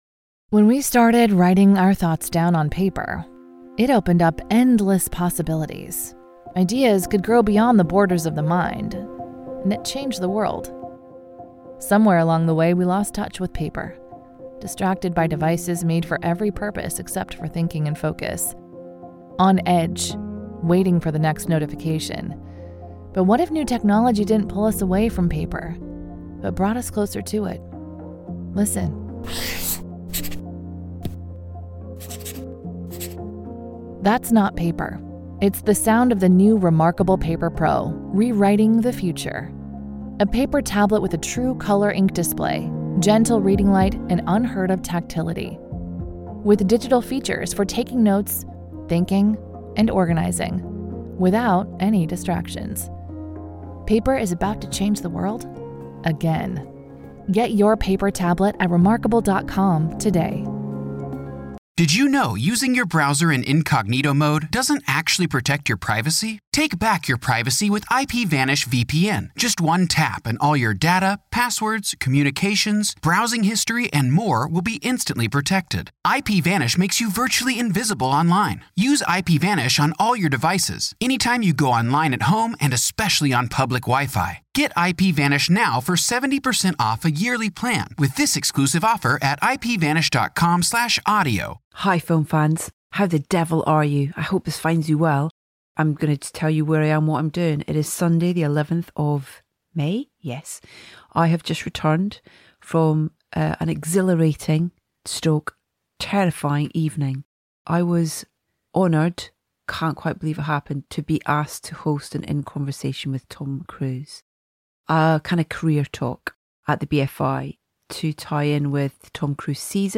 We're so excited to finally bringing you our latest episode of Soundtracking, as first showrunner Tony Gilroy and then composer Brandon Roberts join us to discuss season 2 of Andor, which is streaming now on Disney +.